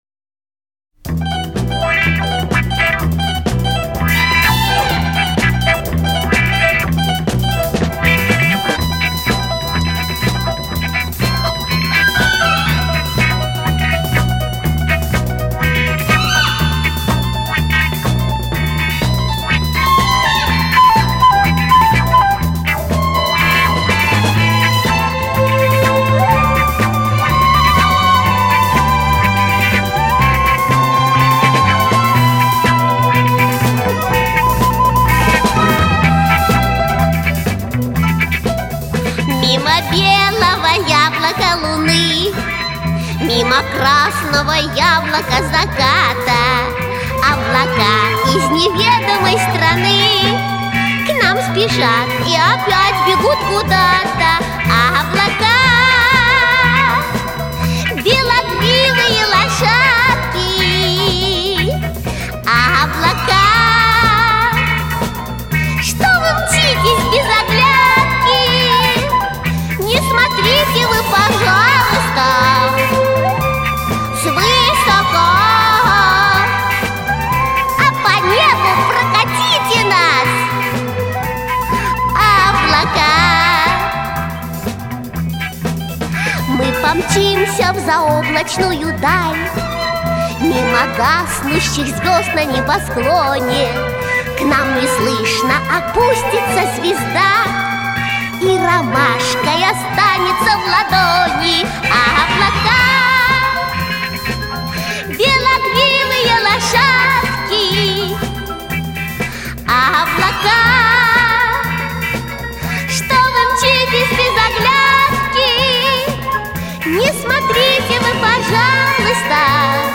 Звучит песня